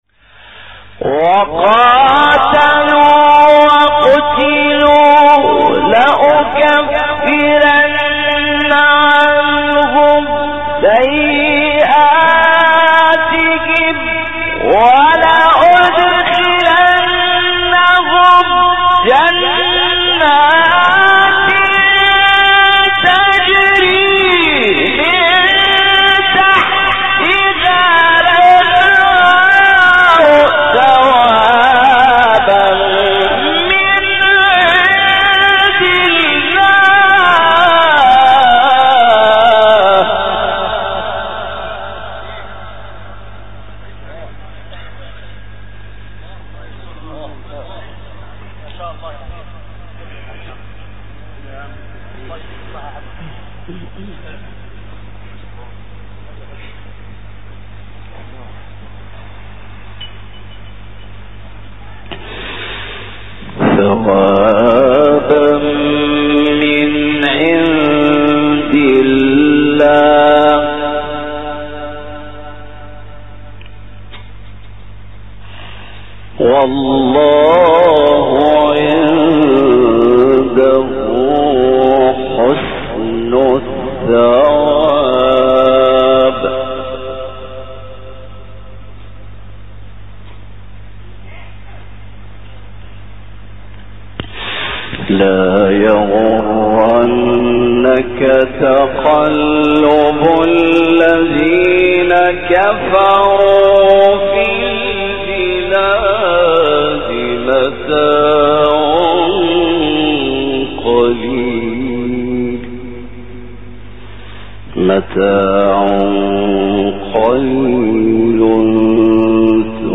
آیه 195-200 سوره آل عمران استاد محمد لیثی | نغمات قرآن | دانلود تلاوت قرآن